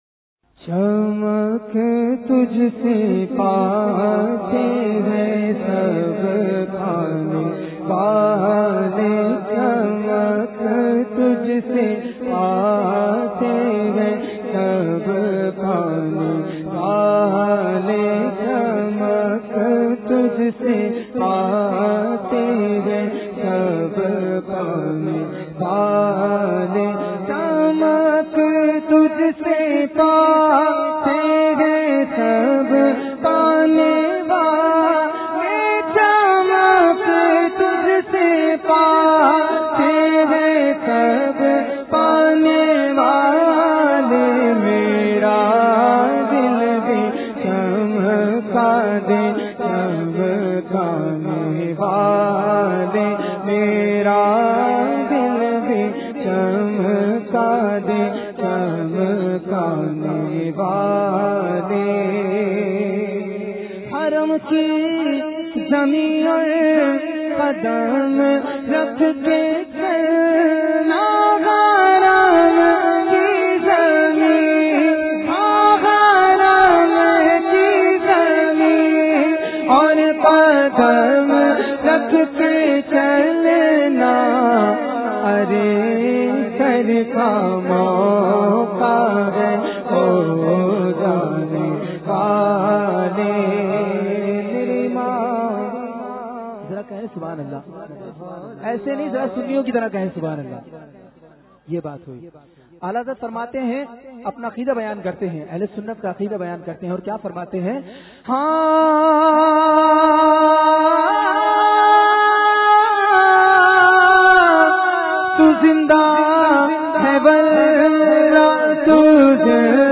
نعت